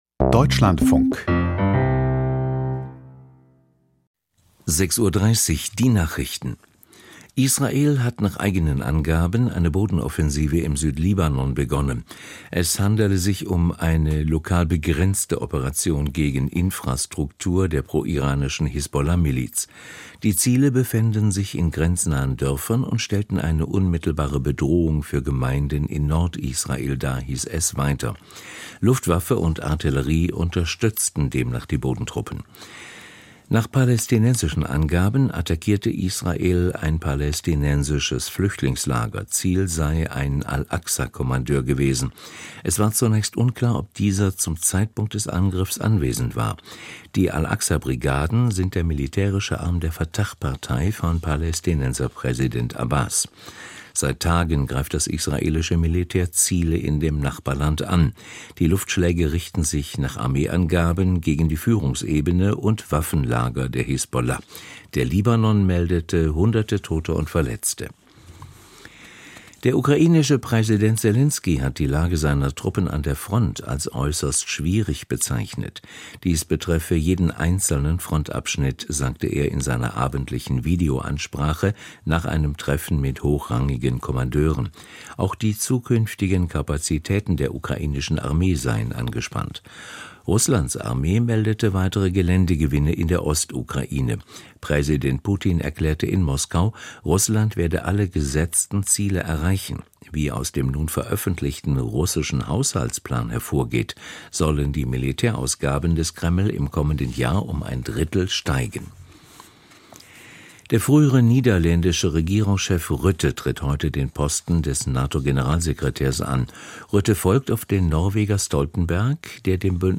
Ampel-Regierung - Gerieben von Lust am Untergang? Interview mit Gyde Jensen, FDP - 24.08.2024